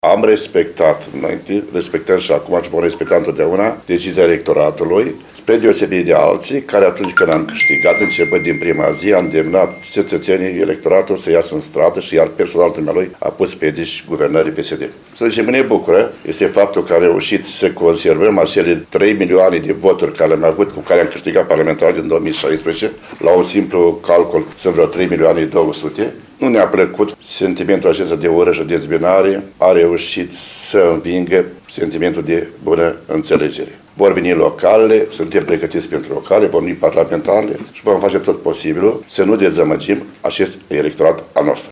În schimb, la sediul PSD Suceava, președintele IOAN STAN a acceptat înfrângerea propriului candidat, dar a spus că atenția trebuie îndreptată de acum spre alegerile de anul viitor.